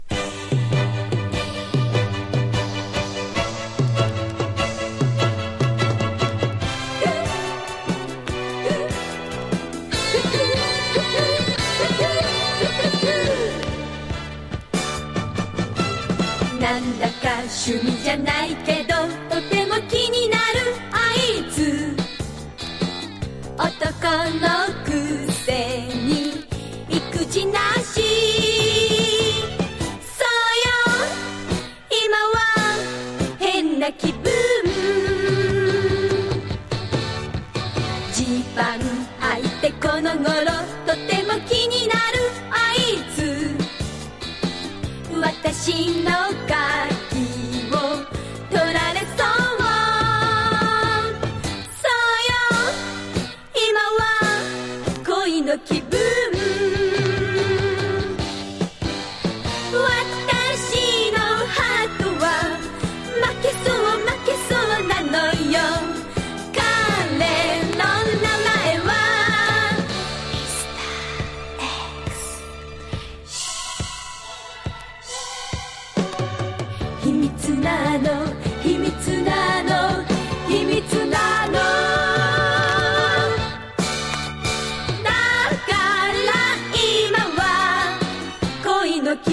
しっとりと歌い上げる昭和歌謡